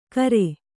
♪ kare